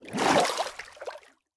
Minecraft Version Minecraft Version latest Latest Release | Latest Snapshot latest / assets / minecraft / sounds / ambient / underwater / exit2.ogg Compare With Compare With Latest Release | Latest Snapshot